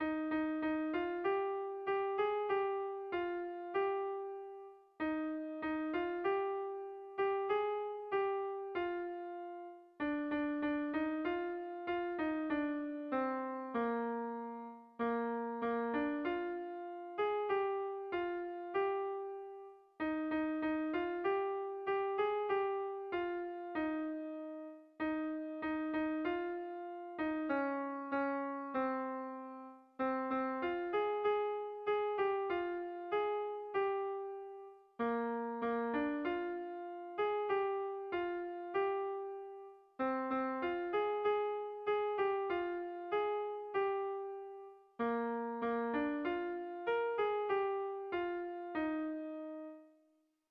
Bertso melodies - View details   To know more about this section
Zortziko handia (hg) / Lau puntuko handia (ip)
A1BA2B2